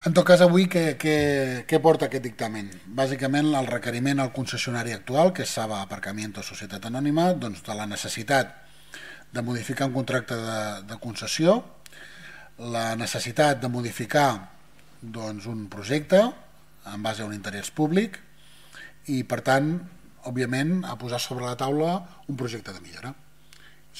Així ho explicava Maurici Jiménez al Ple.
yt1scom-ple-ordinari-de-castellplatja-daro-i-sagaro-dimecres-26-de-maig_KdgKDSUu.mp3